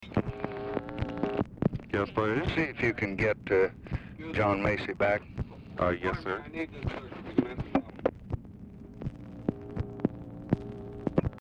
Telephone conversation # 7350, sound recording, LBJ and SIGNAL CORPS OPERATOR, 4/19/1965, time unknown | Discover LBJ
Format Dictation belt
Location Of Speaker 1 LBJ Ranch, near Stonewall, Texas